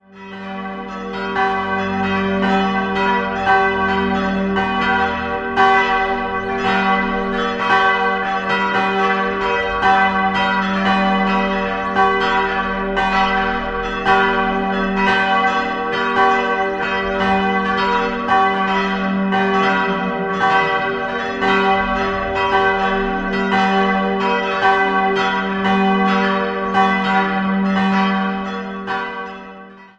4-stimmiges Salve-Regina-Geläute: g'-h'-d''-e''